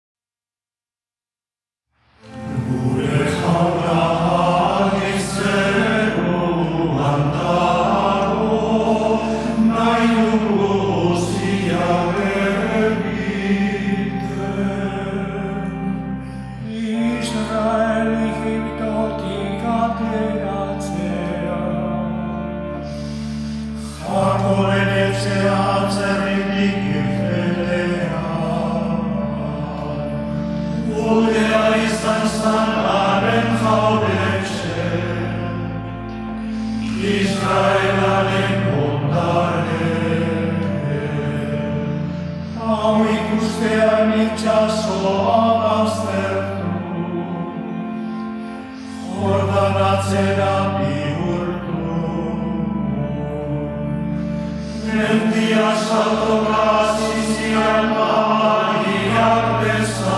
Oparia: Gregorianoa euskaraz